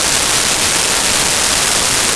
Shower Running Loop 01
Shower Running Loop 01.wav